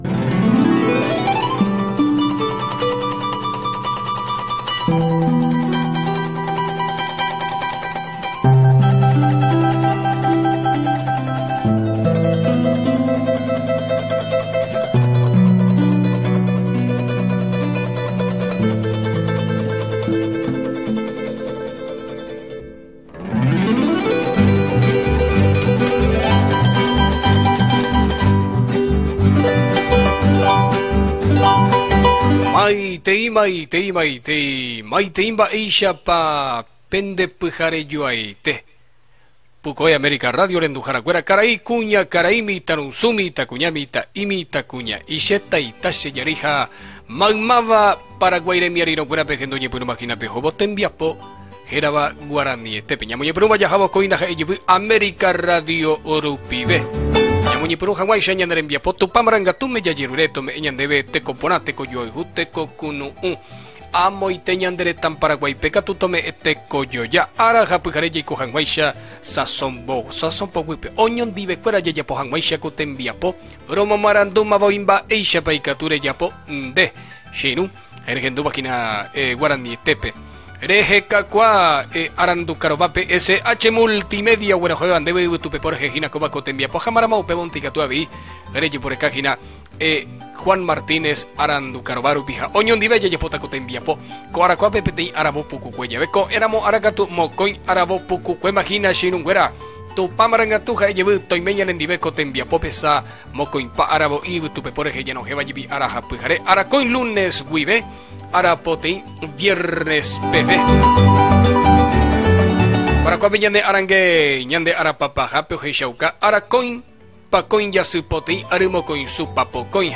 Presentació del programa en llengua guaraní amb el telèfon de participació i tema musical
Entreteniment